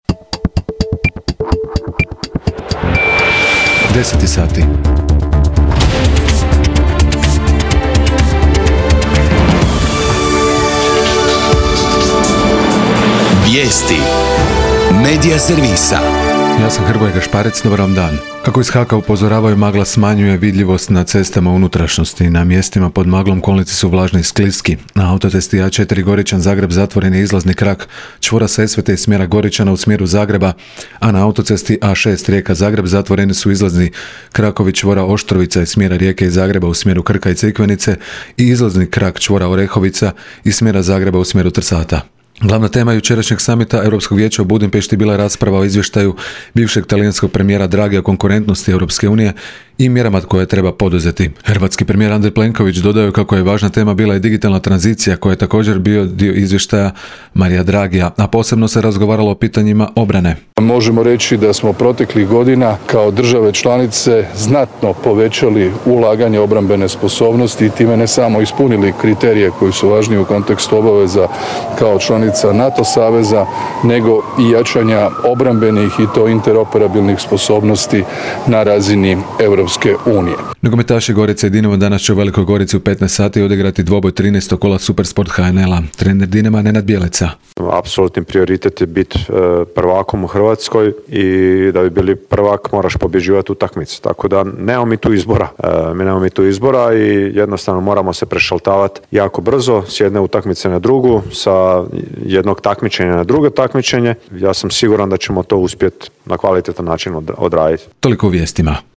VIJESTI U 10